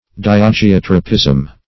Search Result for " diageotropism" : The Collaborative International Dictionary of English v.0.48: Diageotropism \Di`a*ge*ot"ro*pism\, n. (Bot.) The tendency of organs (as roots) of plants to assume a position oblique or transverse to a direction towards the center of the earth.